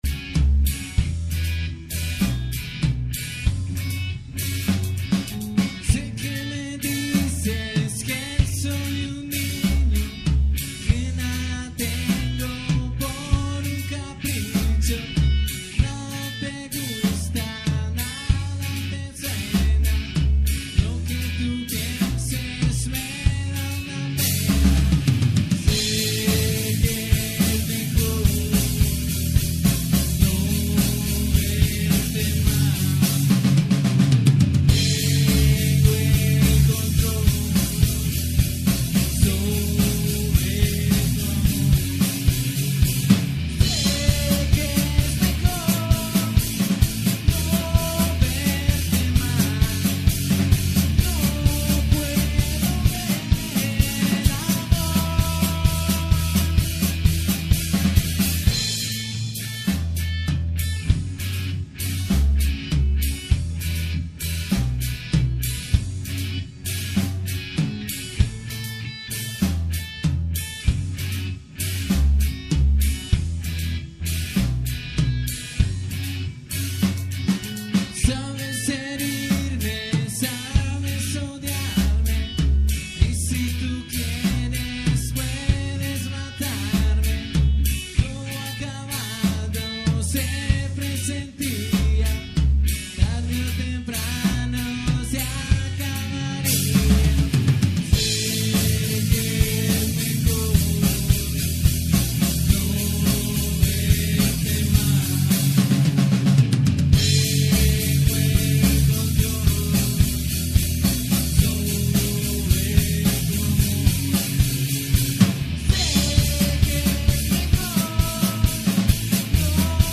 Músico, cantautor y multi-instrumentista
Rock latino